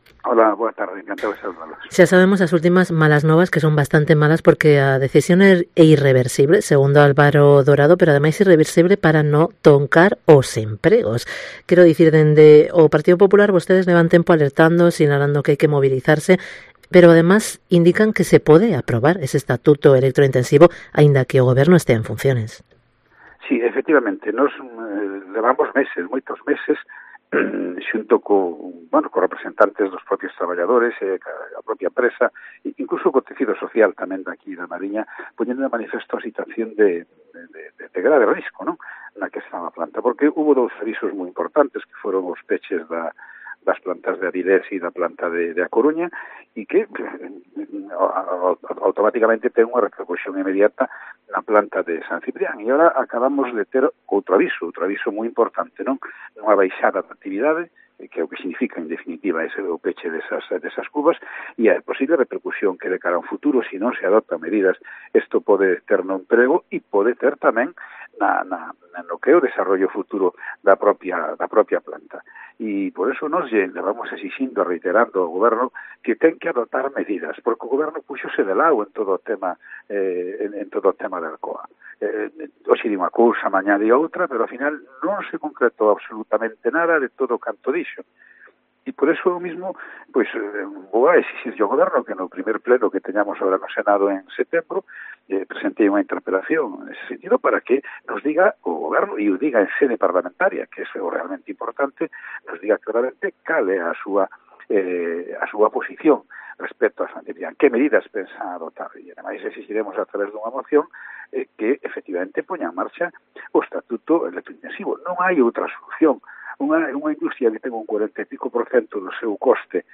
Entrevista con José Manuel Barreiro, senador del PP